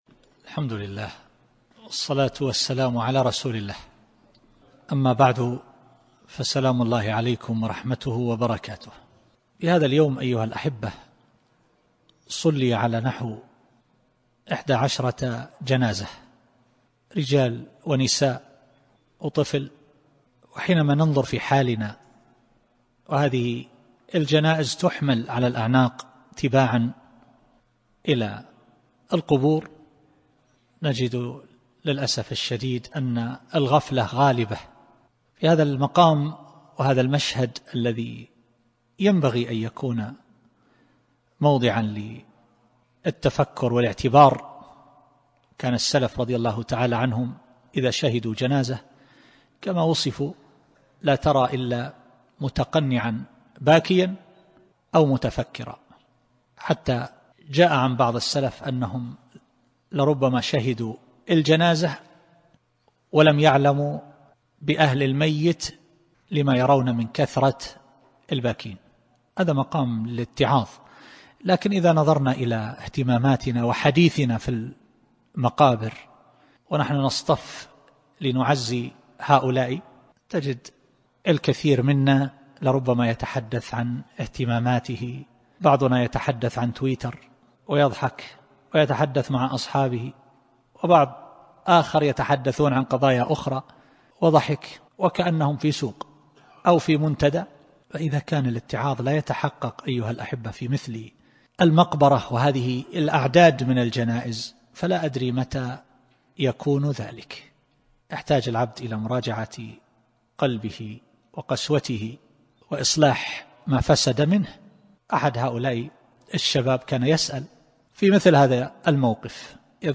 مقطع مميز موعظة إحدى عشرة جنازة في المقبرة